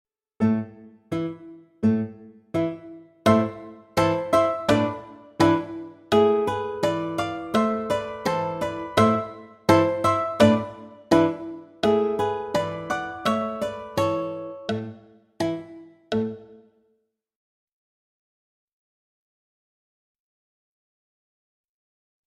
VS Little Ben (backing track)